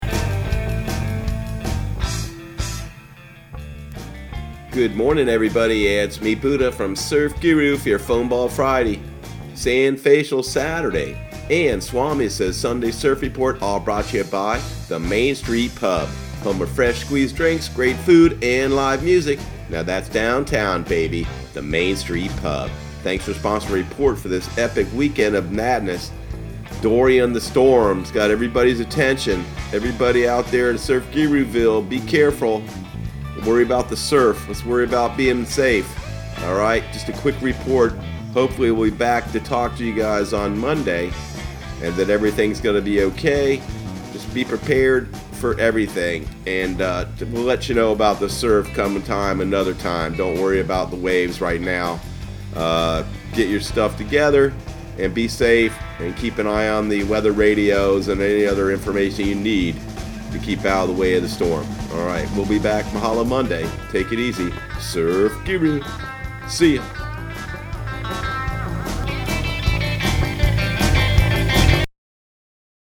Surf Guru Surf Report and Forecast 08/30/2019 Audio surf report and surf forecast on August 30 for Central Florida and the Southeast.